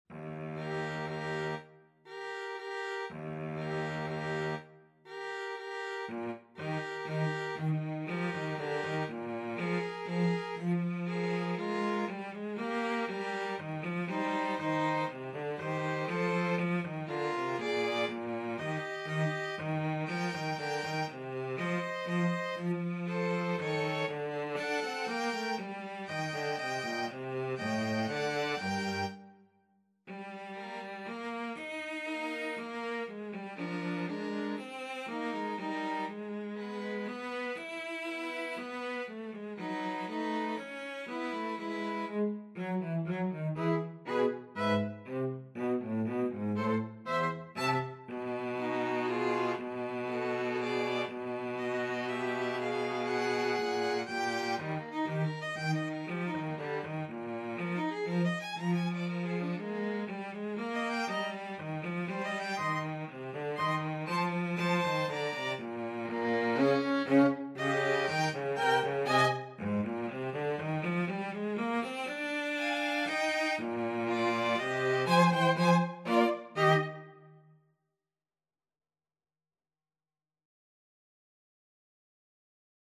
Eb major (Sounding Pitch) (View more Eb major Music for 2-Violins-Cello )
Allegretto pomposo = c.120
2-Violins-Cello  (View more Intermediate 2-Violins-Cello Music)
Classical (View more Classical 2-Violins-Cello Music)